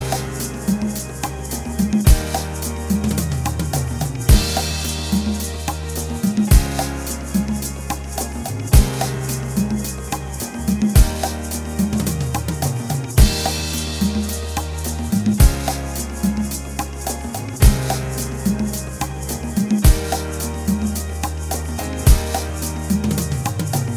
Minus Guitars Rock 6:51 Buy £1.50